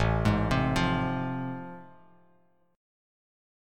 AmM7#5 chord